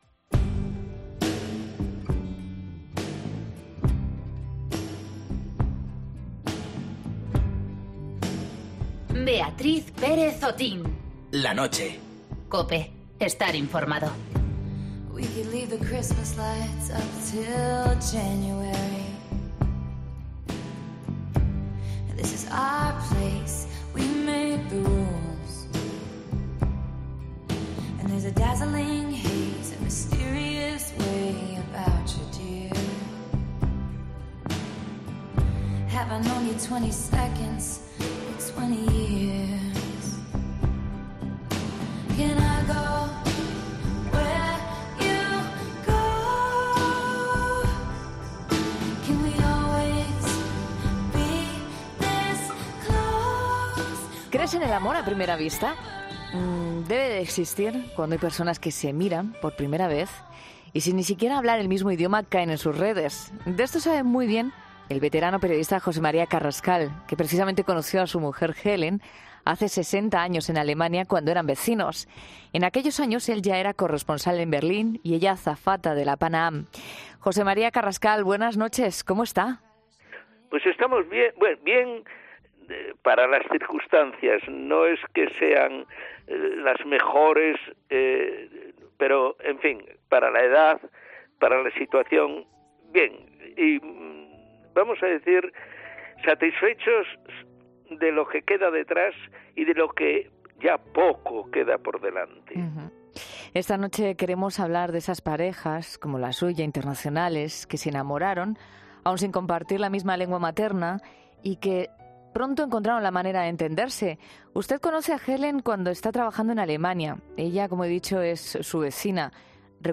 El amor todo lo puede, escuchamos a parejas internacionales que pueden asegurarlo